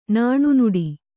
♪ nāṇu nuḍi